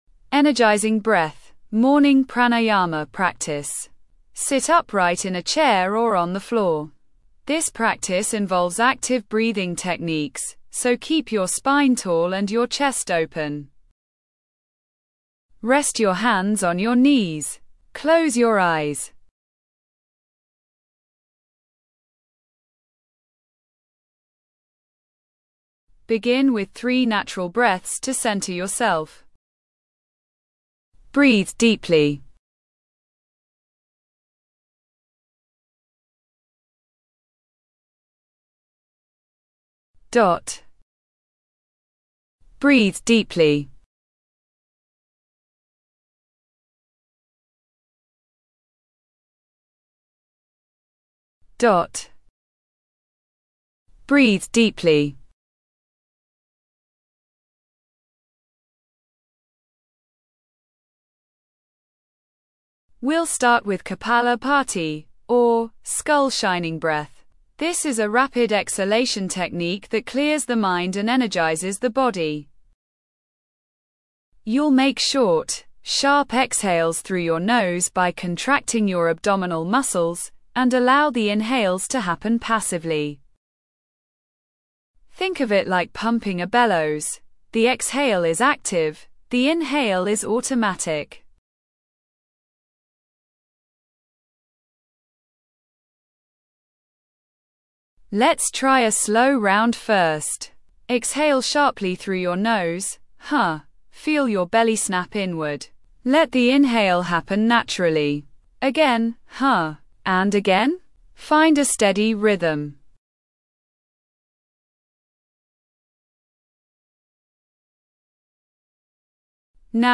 Energizing Breath: A Morning Pranayama and Meditation Practice
energizing-breath-morning-pranayama.mp3